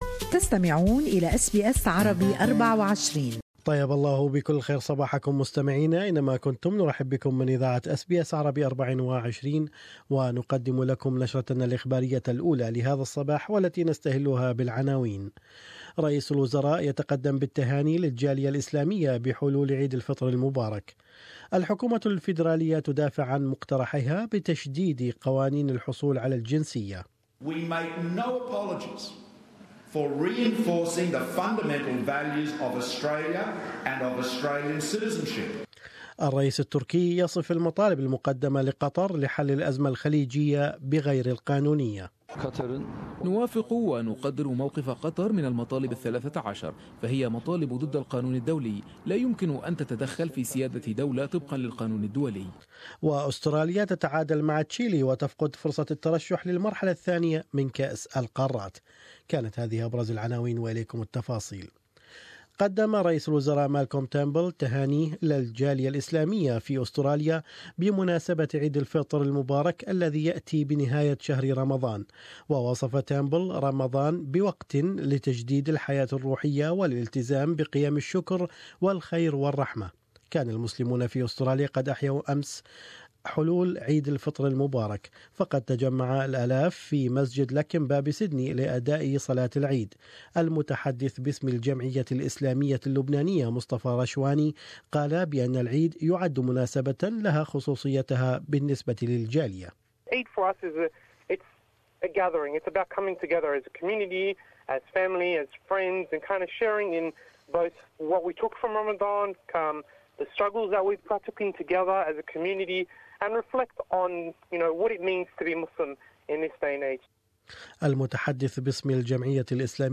Morning news bulletin brought to you in Arabic.